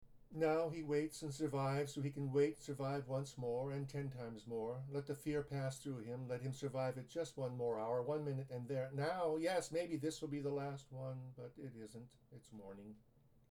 I plan to continue writing poems that need to be recited aloud using only one breath.